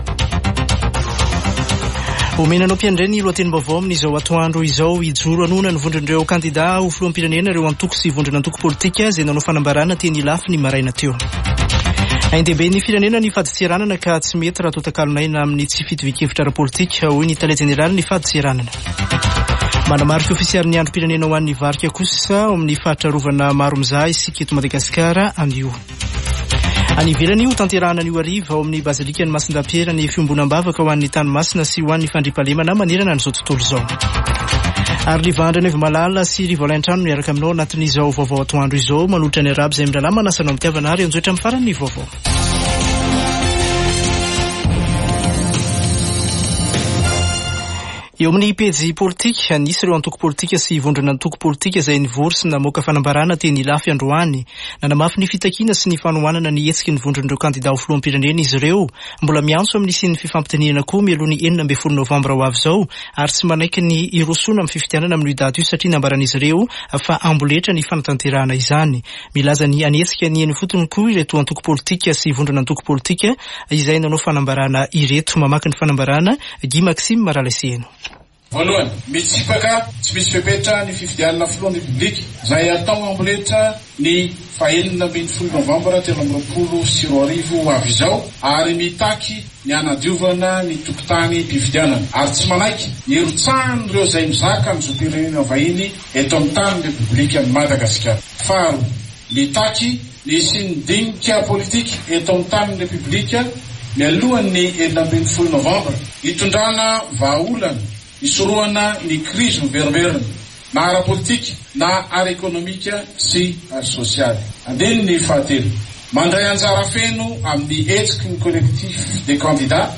[Vaovao antoandro] Zoma 27 ôktôbra 2023